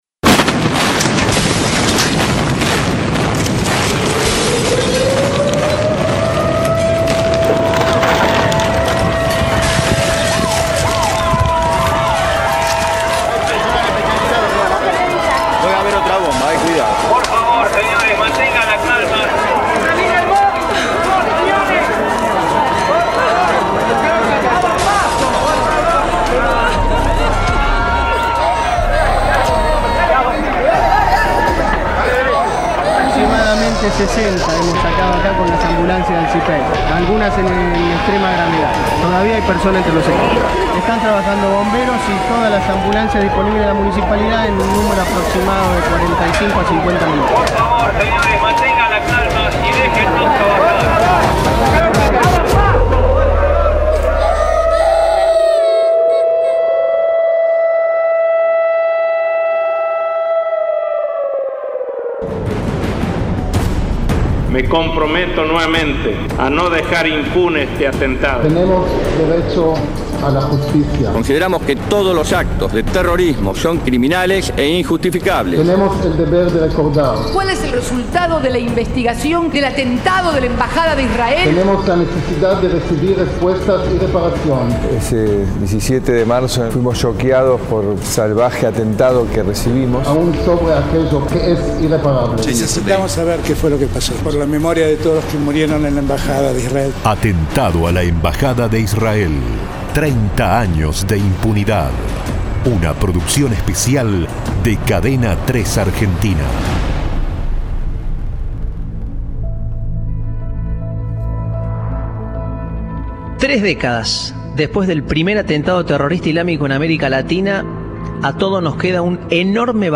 A tres décadas de uno de los capítulos más cruentos de la historia argentina, la radio más federal del país presenta un documental con las voces de sobrevivientes y analistas.